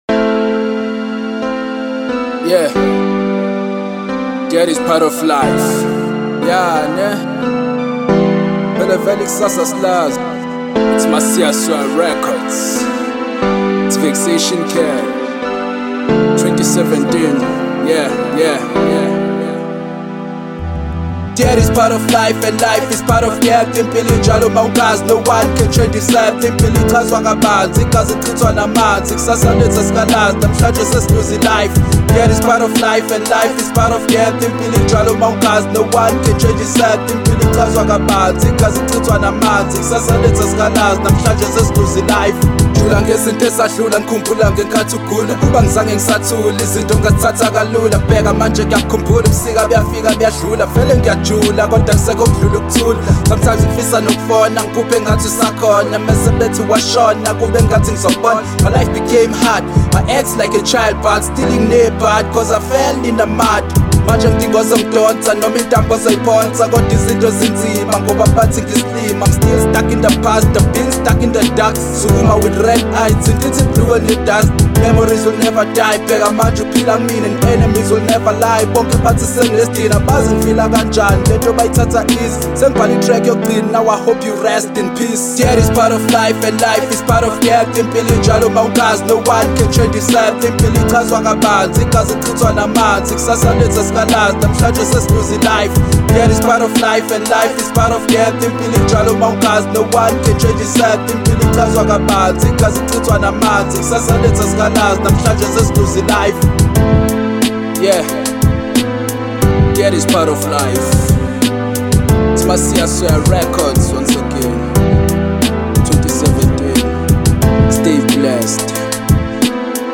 hip hop
emotional, deep, sad and soulful rap